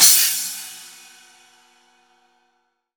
16 CHINA.wav